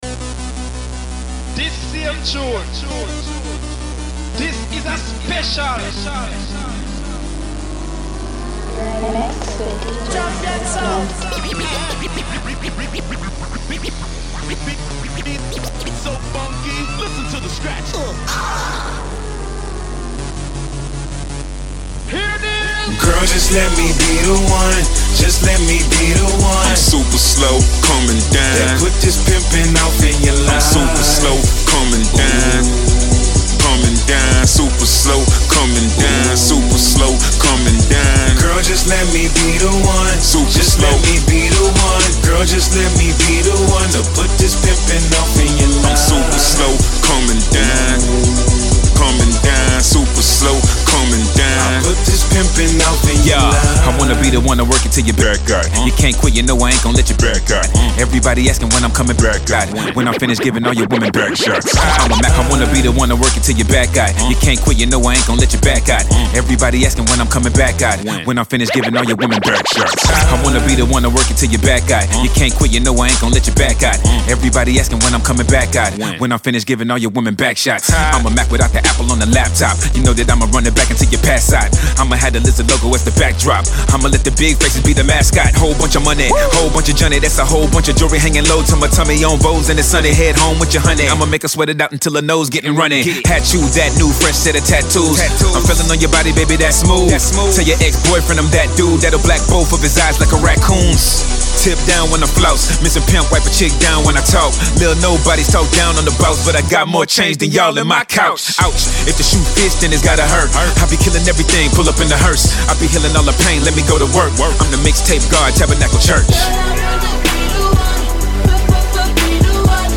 Genre: Hip Hop.